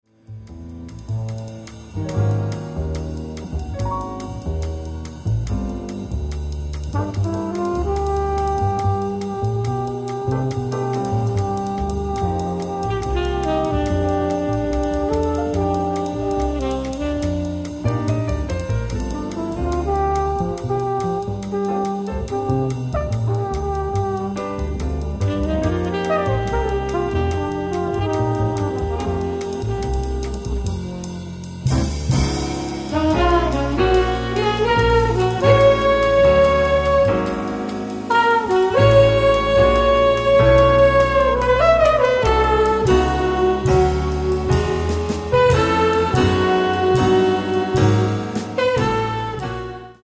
tromba e flicorno
piano
contrabbasso
batteria